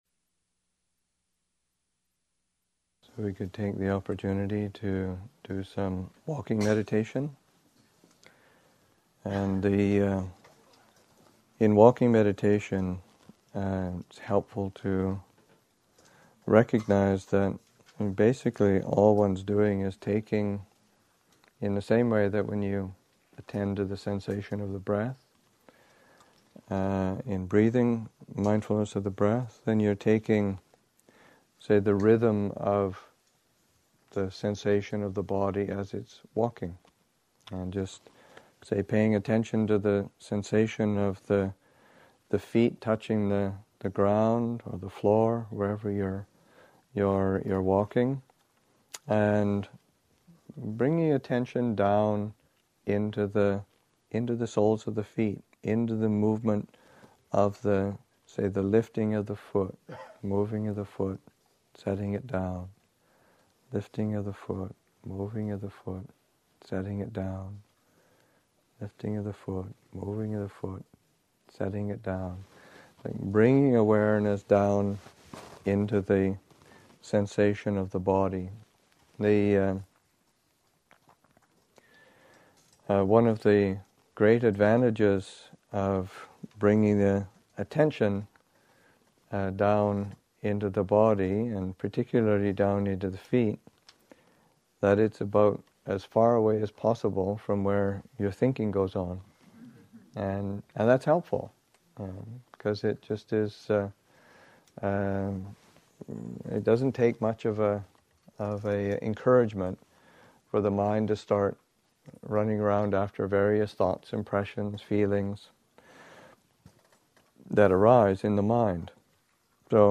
Guided meditation: The rythym of the sensation of the body as it is walking.